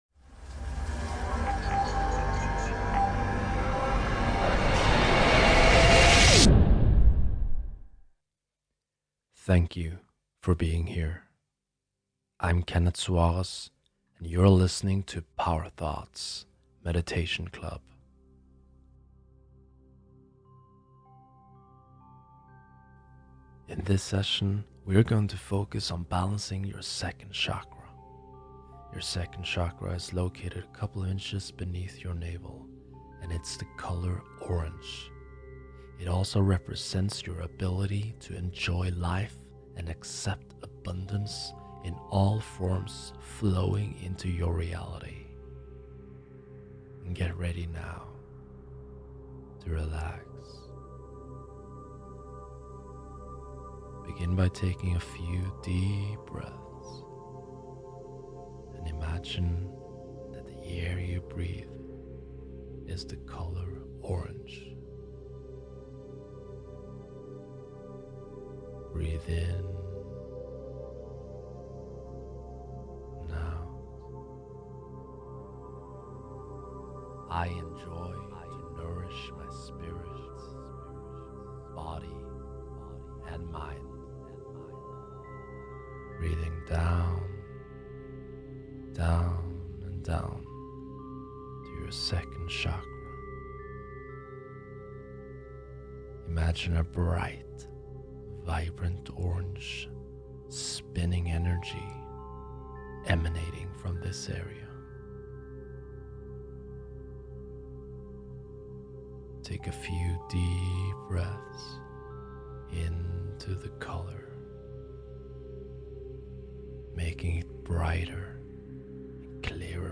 To enhance the healing of your Sacral Chakra Meditation we have used the Sacral Chakra Solfeggio 417 Hz.
2ActivatingQiFlowOfSacralChakraMeditationEN.mp3